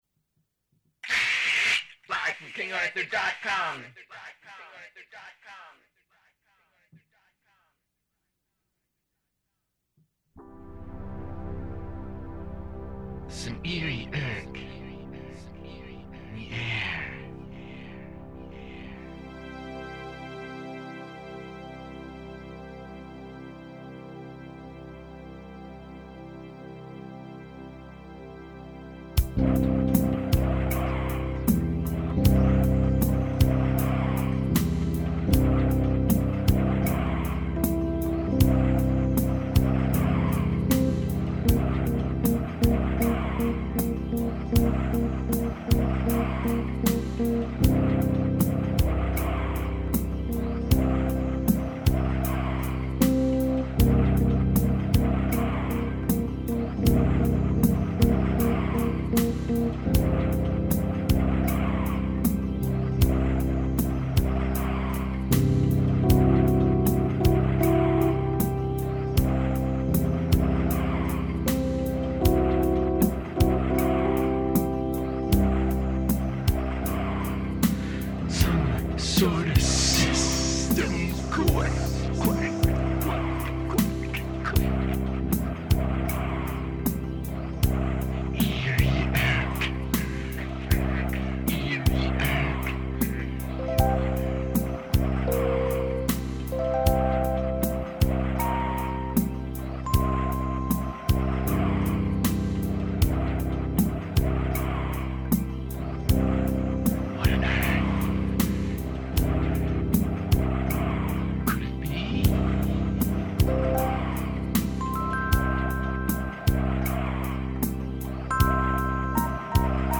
C C7 C(b5)
78 BPM / Organ Ballad
A 1-track live and extemporaneous stereo recording.
Vocals, Keyboards, Synthesizers, Controller and Computer